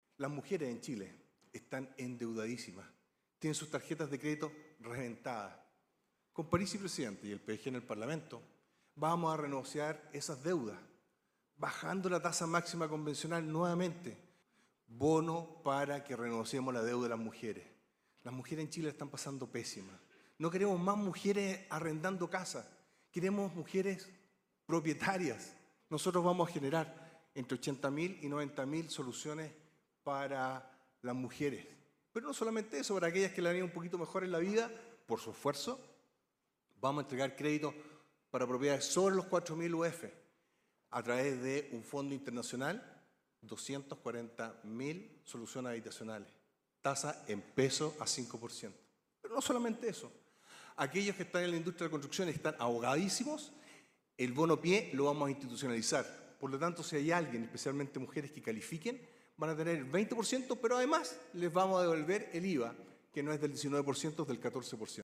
El siguiente candidato en dirigirse al empresariado fue Franco Parisi, del PDG, quien sostuvo en primer lugar que Chile “no es facho ni comunacho” y luego ofreció renegociar las deudas de las mujeres y soluciones habitacionales específicas.